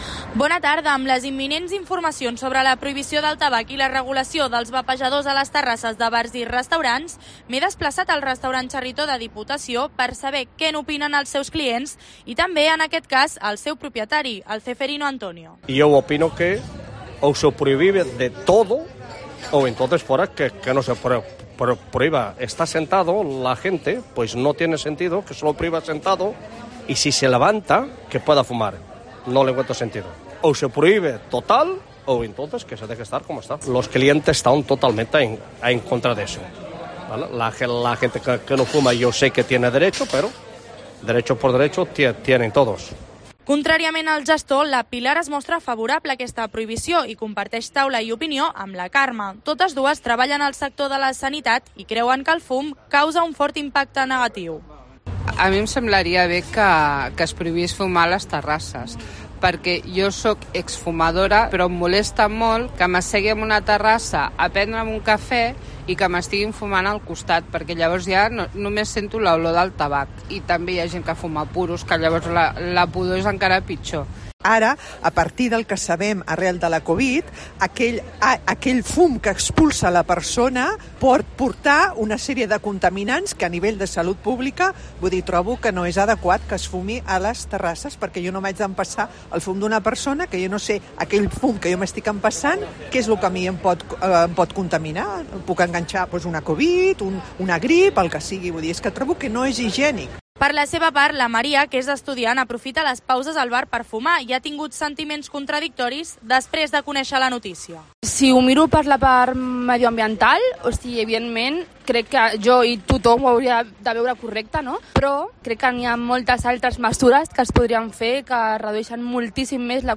El debate está en la calle, ciudadanos opinan sobre la posible prohibición de fumar en terrazas